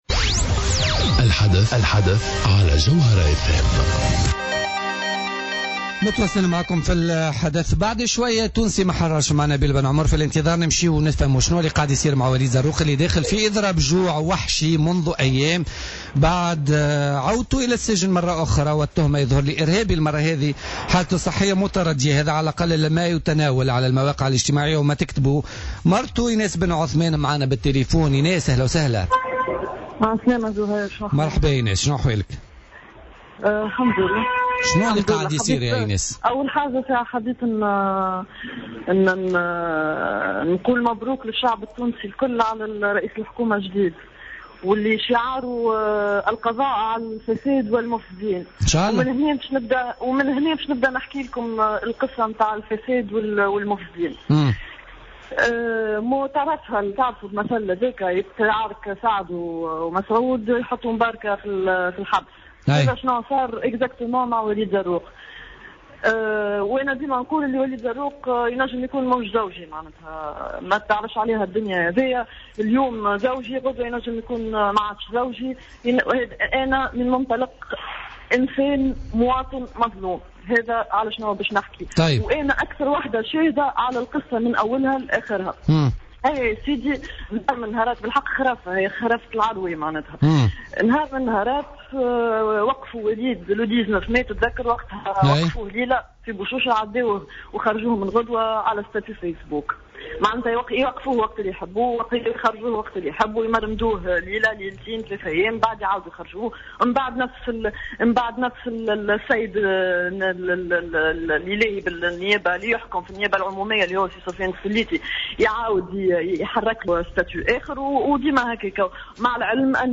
مداخلة لها في برنامج الحدث